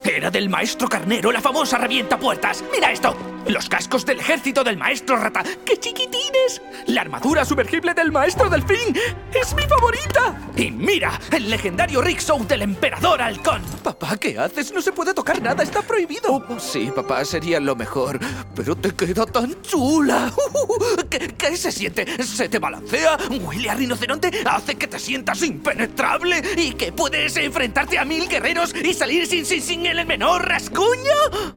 Locutor profesional, actor de doblaje y técnico de sonido con más de 10 años de experiencia en el mundo del doblaje y la locución.
kastilisch
Sprechprobe: Sonstiges (Muttersprache):